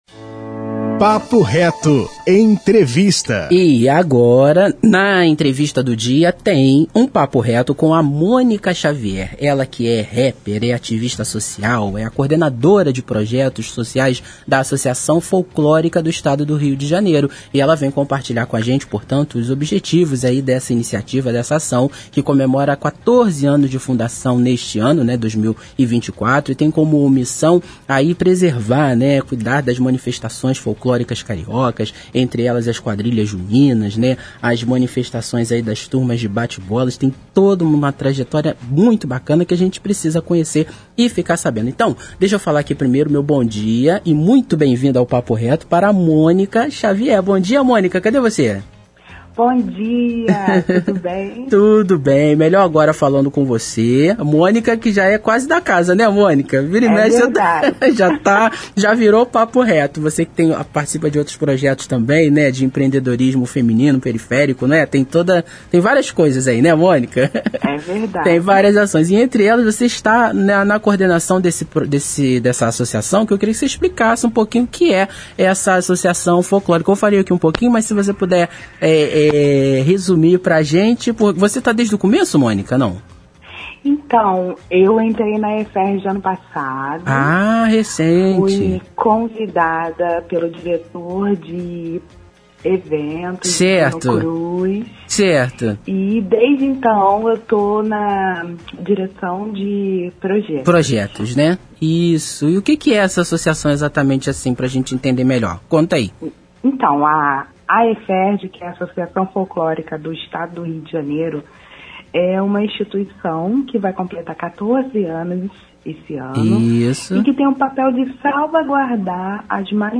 Na entrevista do Programa Papo Reto